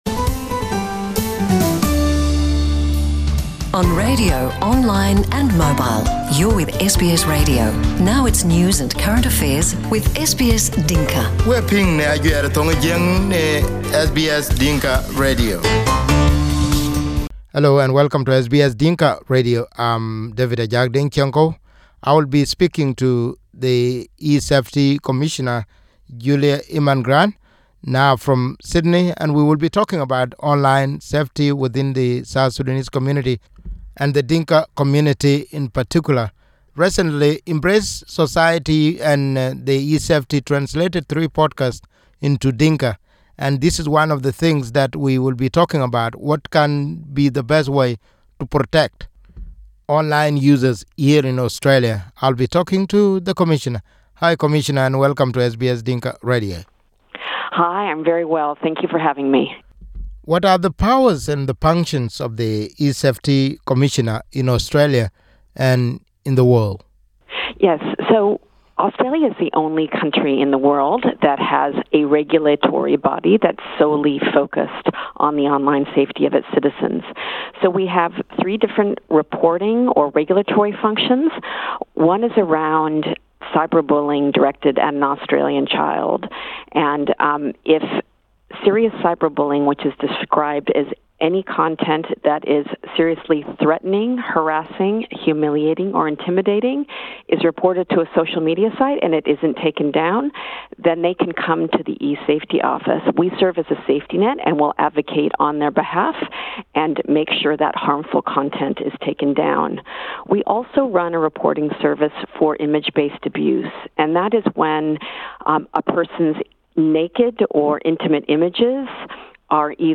(Podcast in English) eSafety Commissioner Julie Inman Grant in this interview with SBS Dinka warn people who are using the internet in wrong ways.